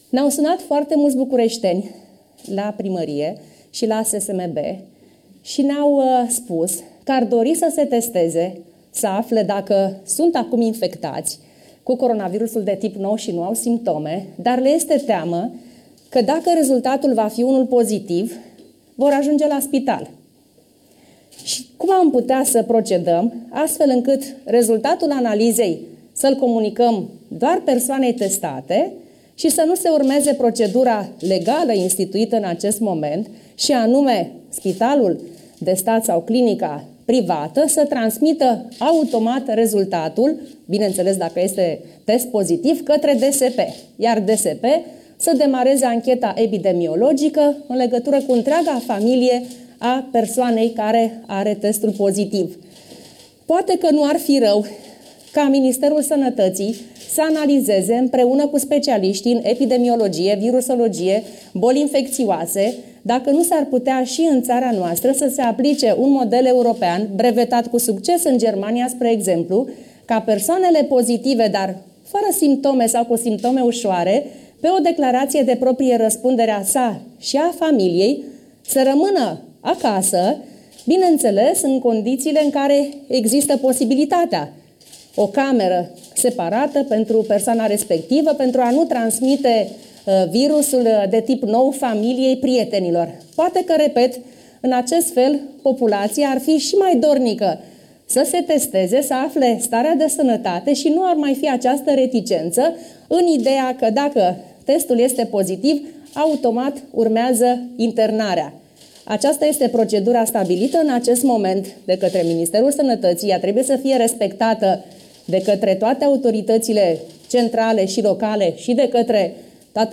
“Ne-au sunat foarte mulți bucureșteni și ne-au ne-au spus că ar dori să se testeze, să afle dacă sunt acum infectați cu coronavirusul de tip nou și nu au simptome, dar le este teamă că, dacă rezultatul este unul pozitiv,  vor ajunge la spital, și aum am putea face ca rezultatul testului să fie comunicat doar persoanei testate şi să nu se urmeze procedura legală, şi anume spitalul sau clinica privată să transmită imediat rezultatul, dacă este pozitiv, către DSP, care să facă o anchetă epidemiologică. Poate nu ar fi rău ca Ministerul Sănătăţii să analizeze, împreună cu specialiştii, dacă nu s-ar putea ca şi în ţara noastră să se aplice un model european brevetat cu succes în Germania, de exemplu, ca persoanele pozitive, dar fără simptome sau cu simptome uşoare, pe o declaraţie pe proprie răspundere a sa şi a familiei, să rămână acasă, dacă există posibilitatea”, a declarat Gabriela Firea, duminică, într-o conferinţă la sediul PSD.